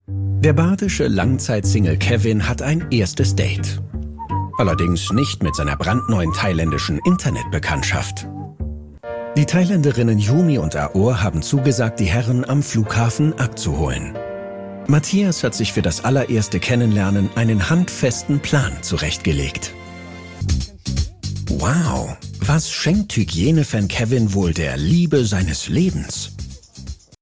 kommentar-joyn-amore unter palmen nr.2